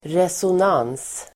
resonans substantiv (bildligt " gensvar, förståelse"), resonance [figuratively " response, understanding"] Uttal: [reson'an:s (el. -'ang:s)] Böjningar: resonansen Definition: genklang Sammansättningar: resonansbotten (sounding board)